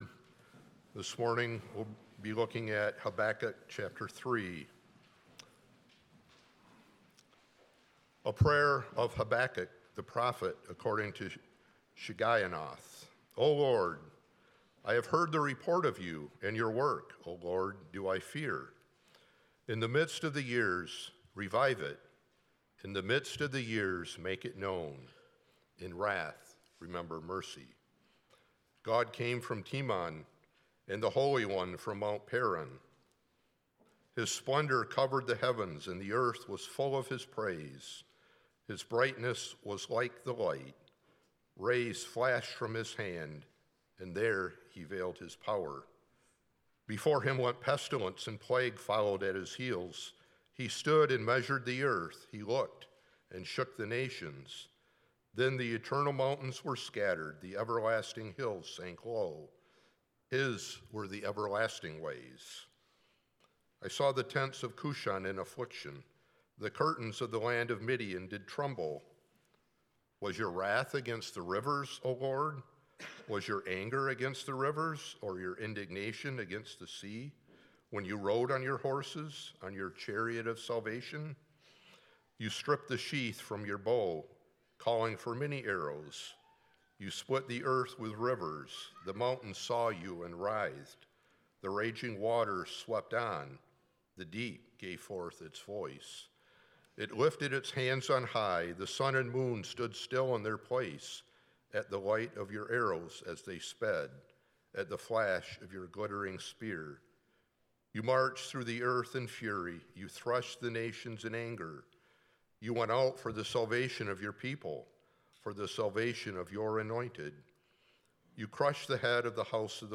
5.25.25 sermon.m4a